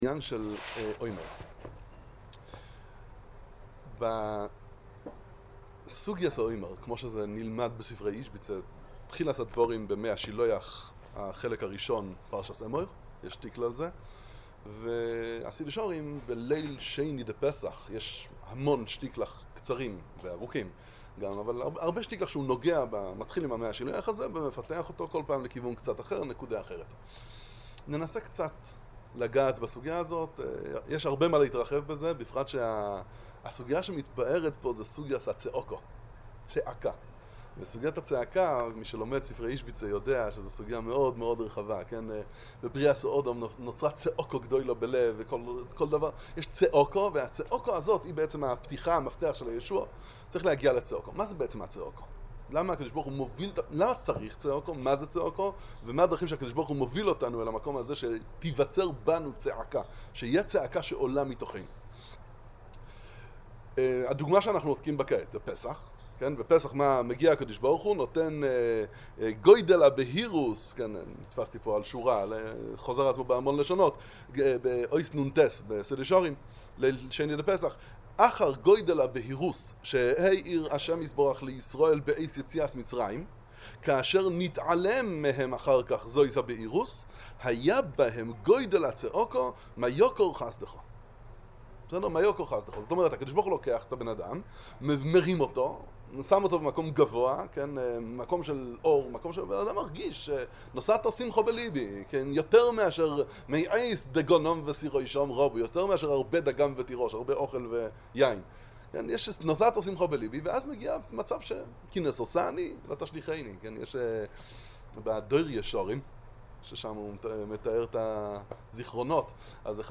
השיעור בירושלים בספרי איז'ביצא